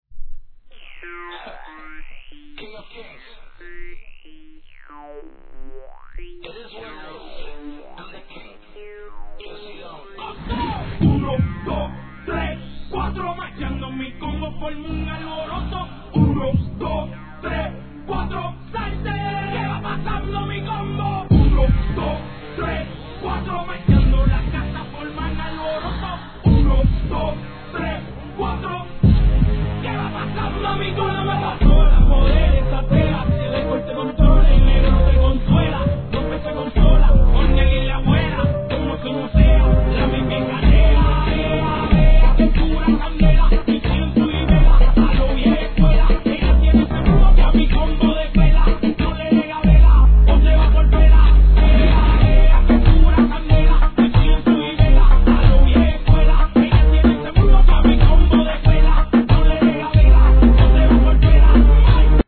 HIP HOP/R&B
■REGGAETON No. タイトル アーティスト 試聴 1.